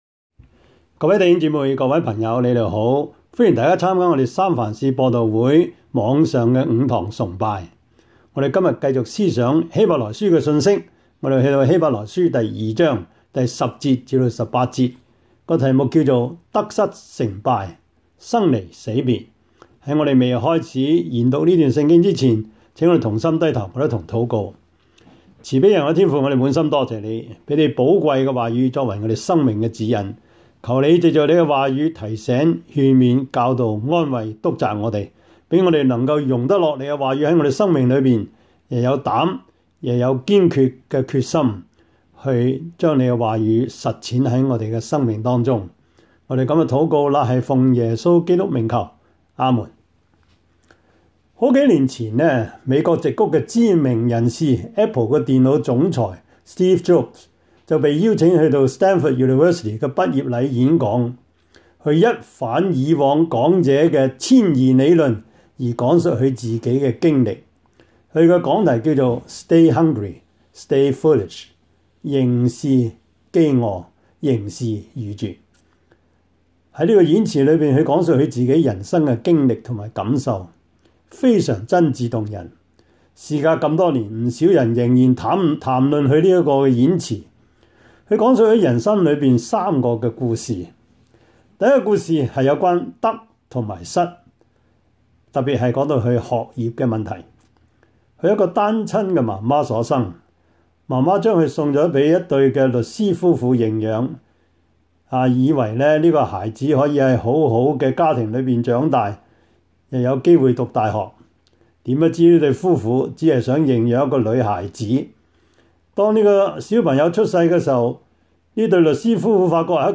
Service Type: 主日崇拜
Topics: 主日證道 « 基督受苦得勝的榜樣 夫妻相處之道 – 第十課 »